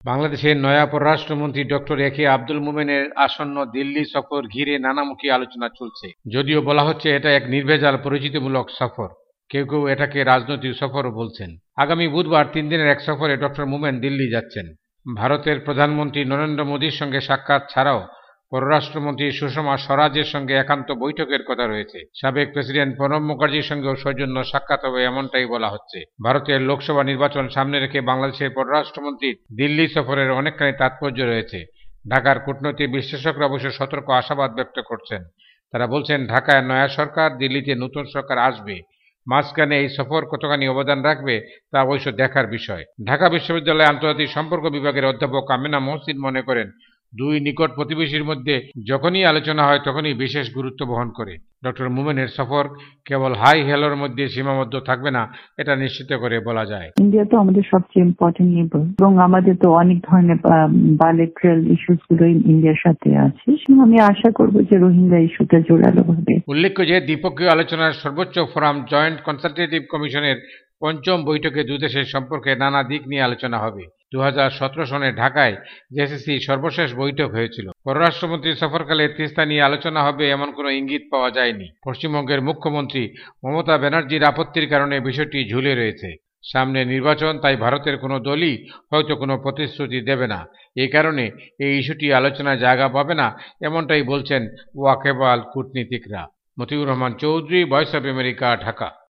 রিপোর্ট।